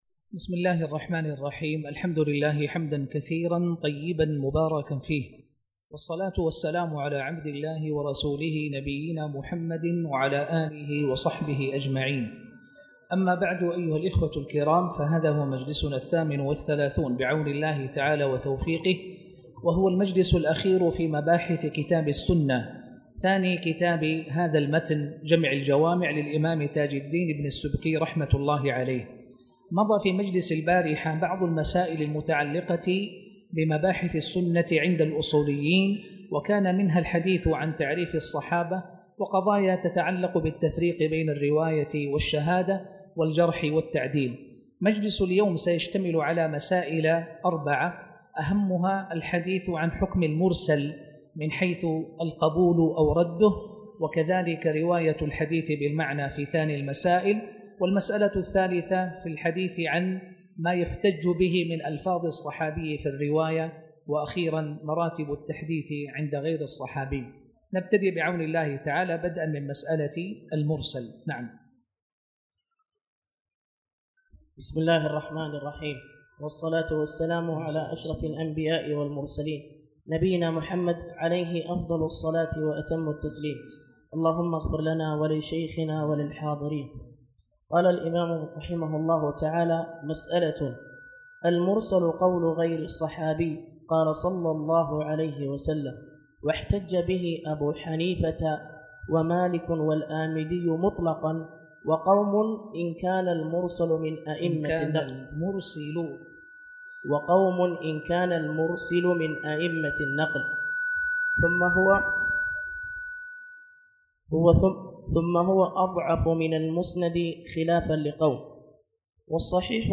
تاريخ النشر ١٤ صفر ١٤٣٨ هـ المكان: المسجد الحرام الشيخ